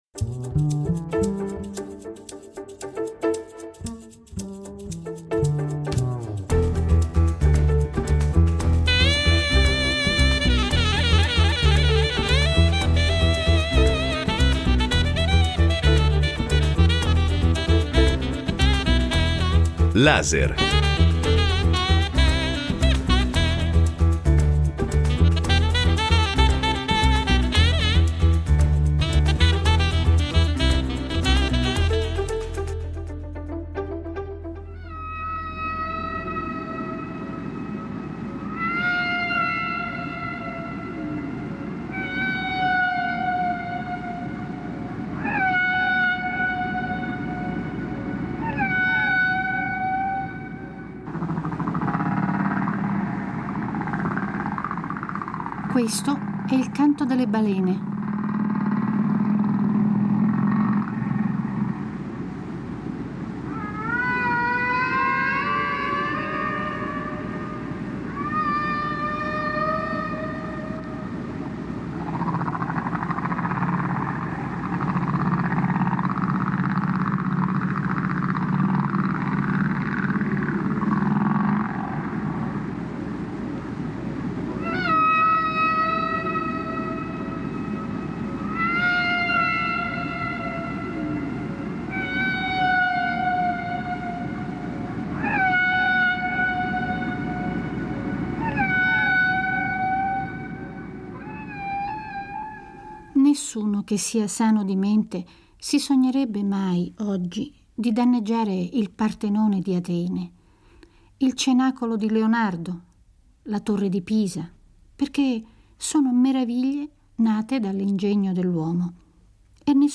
Alla scoperta di un mondo, quello delle balene, raccontato da chi quei mammiferi li ha visti da vicino fino a toccarli. La radio porta gli ascoltatori a comprendere questi animali unici anche da una prospettiva sonora.